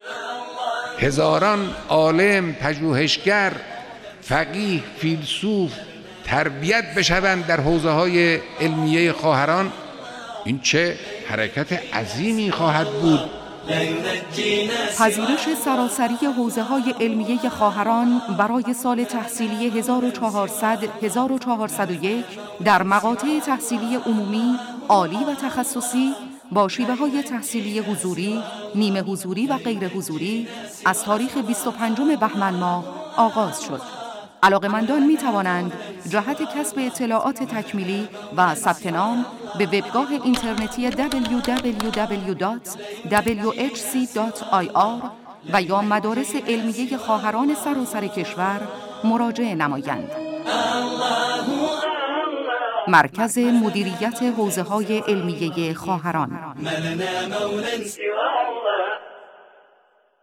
فایل تیزر صوتی پذیرش سراسری حوزه‌های علمیه خواهران قابل استفاده در شبکه‌های رادیویی